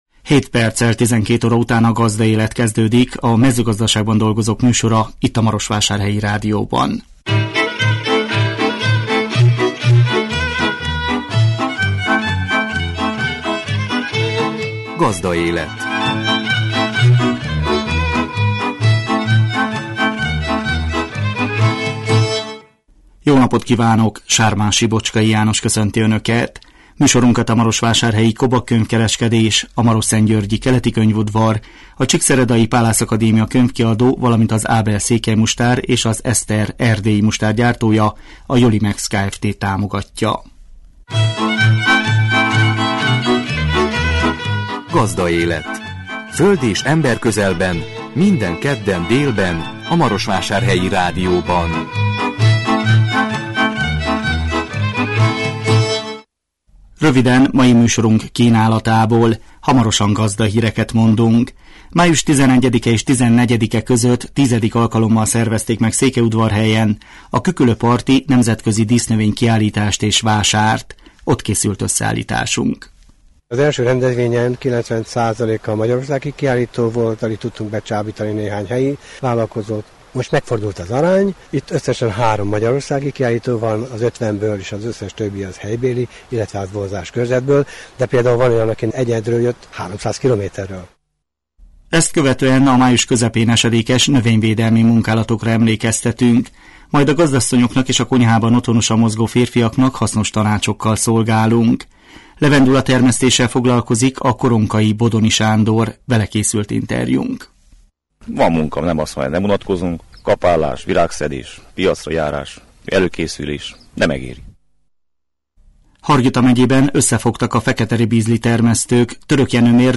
A 2017 május 16-án jelentkező műsor tartalma: Gazdahírek, Május 11-e és 14-e között X. alkalommal szervezték meg Székelyudvarhelyen a Küküllő parti nemzetközi dísznövény kiállítást és vásárt. Ott készült összeállításunk. Ezt követően a május közepén esedékes növényvédelmi munkálatokra emlékeztetünk.
Vele készült interjúnk.